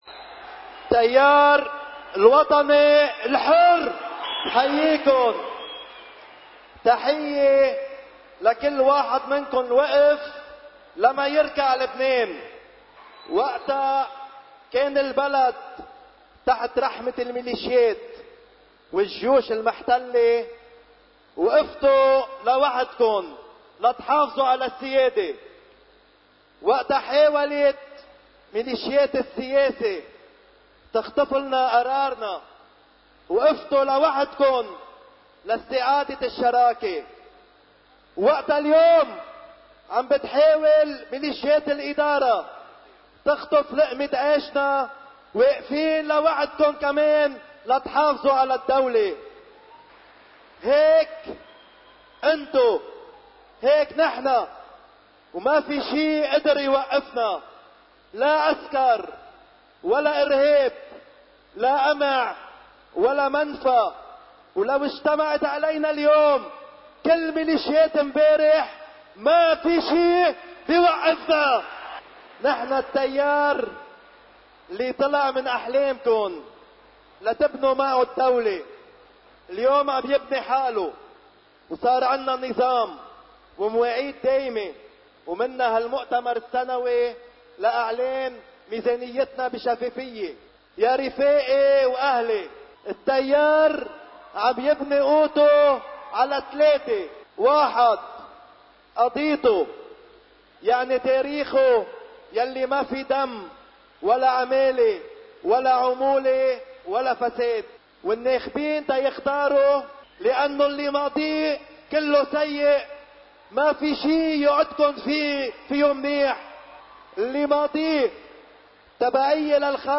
كلام رئيس التيار الوطني الحرّ في حفل إطلاق لوائح التيار الوطني الحر، وتكتّل التغيير والإصلاح من “الفوروم بي دي بيروت” (الجزء الأول – 24 آذار 2018)